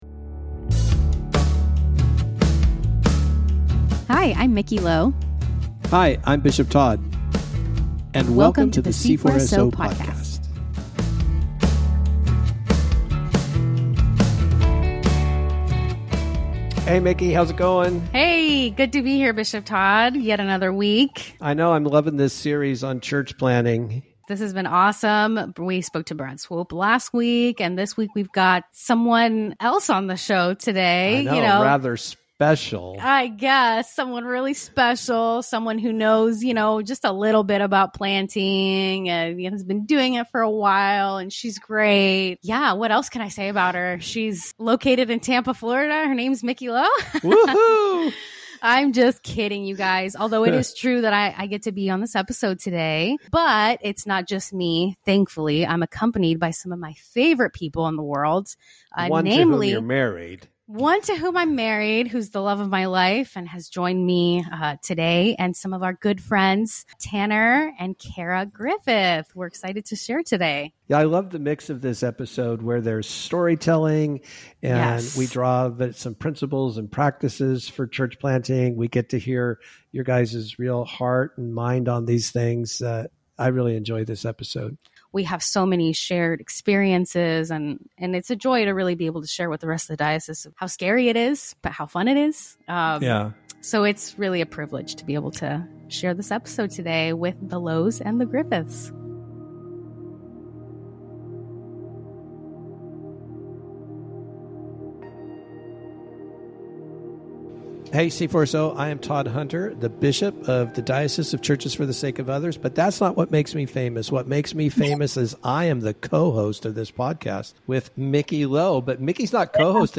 You'll hear both couples describe the joys and pains of church planting, including what they've learned and how they've been [...]